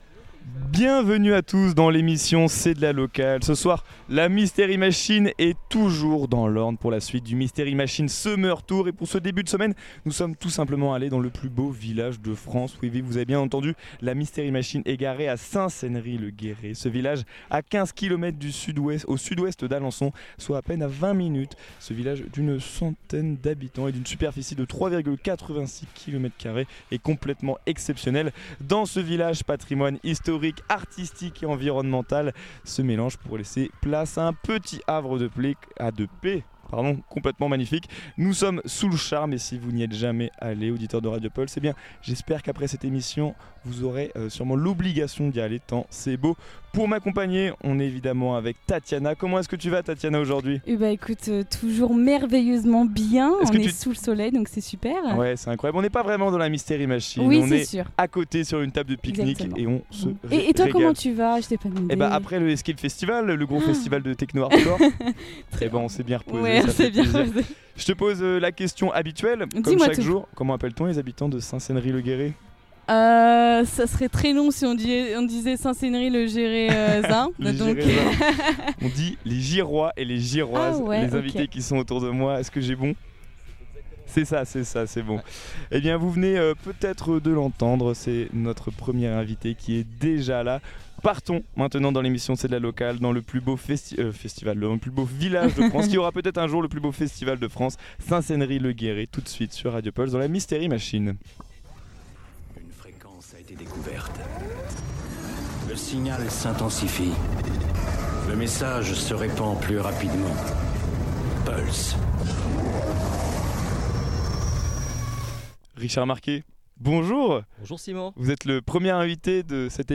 On vous présente des artistes Normand avec la rubrique "Le local de l'étape" co-produit par le collectif de radios musiques actuelles Normand "La Musicale" Cet été, nous organisons la Mystery Machine Summer Tour, c’est-à-dire une émission en direct de 18h à 19h, diffusée depuis différentes villes et villages de l’Orne et de la Normandie. Dans cette émission, l’objectif est de présenter la ville, les activités à y faire, son histoire, les acteurs de la vie culturelle et associative, ainsi que les différents événements prévus cet été.
Petite nouveauté pour ce village, nous voulions profité du beau temps et nous nous sommes exceptionnellement mis sur les tables dans un petit écrin de verdure, mais promis la Mystery Machine à elle aussi profitée de ce beau soleil auprès de nous ! Quant à nos invités, nous avons reçu, Richard Marquet, Maire de la ville.